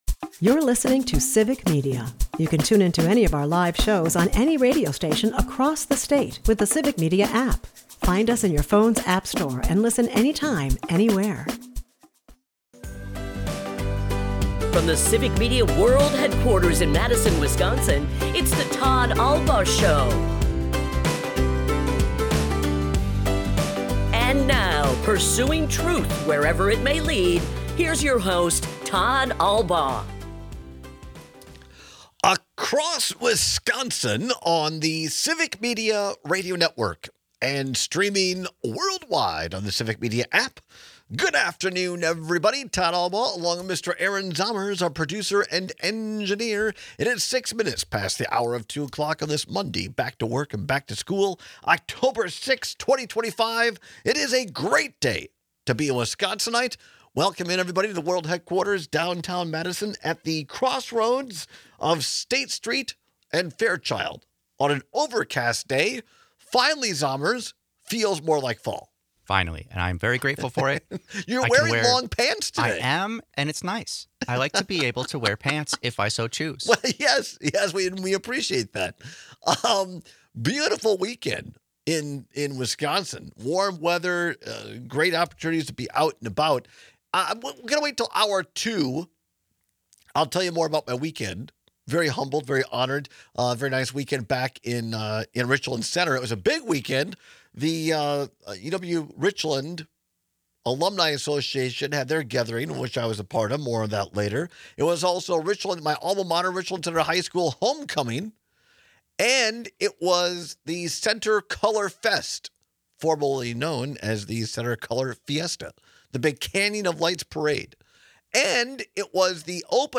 Saturday’s Brewers game was an absolute blowout, putting Milwaukee up 1-0 in this series against the Cubs. We take a listen to comments from Cubs manager Craig Counsell and Brewers manager Pat Murphy on how they and their teams are approaching this series.